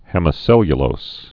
(hĕmĭ-sĕlyə-lōs, -lōz)